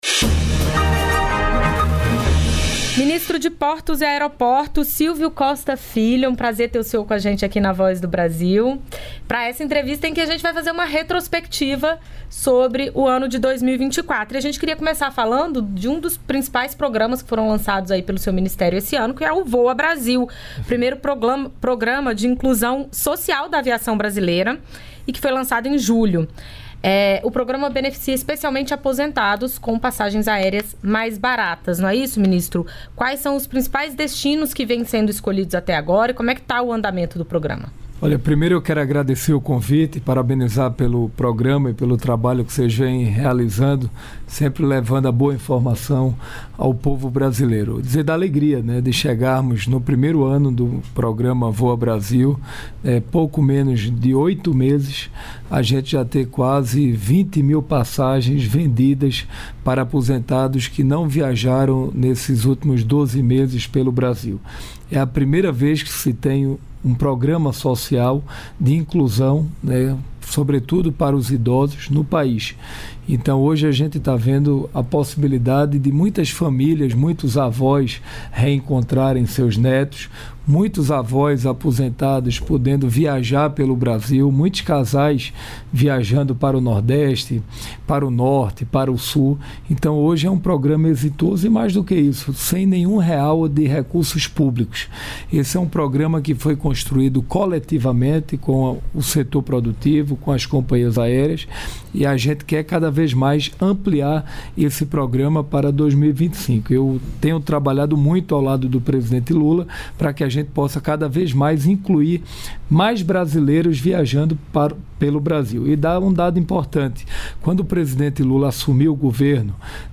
Entrevistas da Voz Camilo Santana, ministro da Educação O ministro falou sobre a aprovação pelo Congresso Nacional do Juros Por Educação.